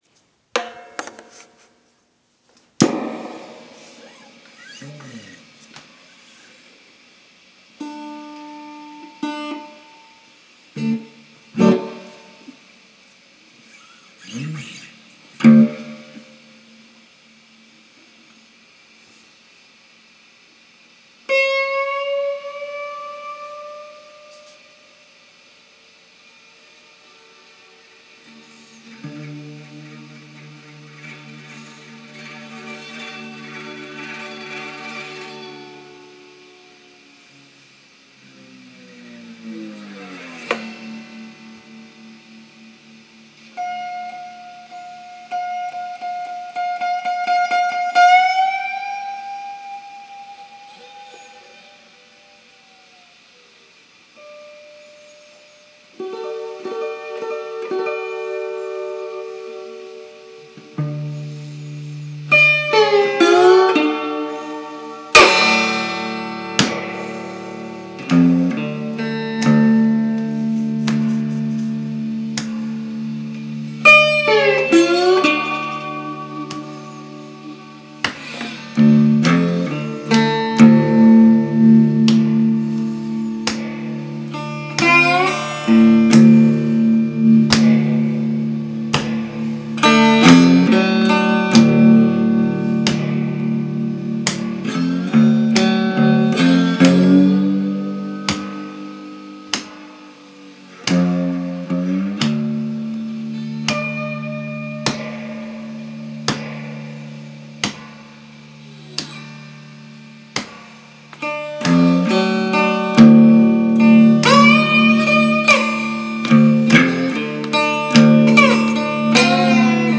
slow growl
slow_growl1.wav